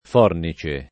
fornice [ f 0 rni © e ] s. m.